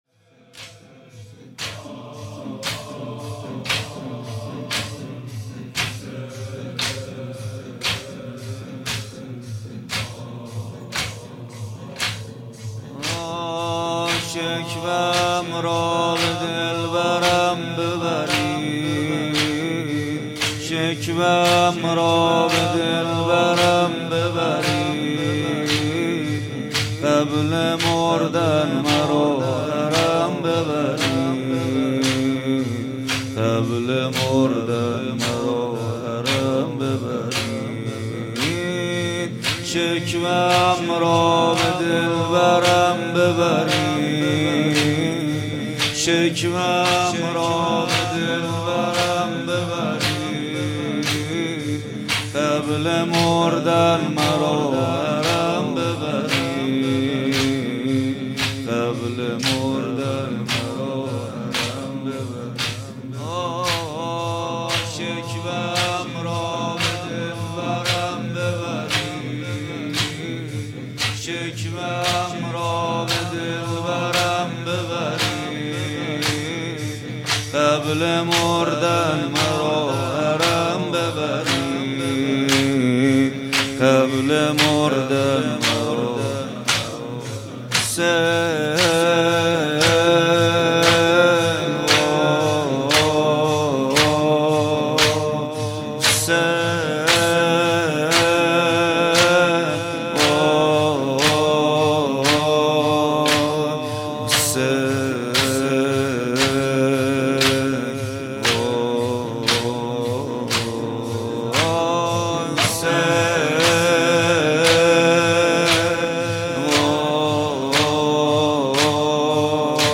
مداحی جدید کربلایی محمد حسین پویانفر مراسم هفتگی هیات ریحانة النبی(س) 03 آذر 1400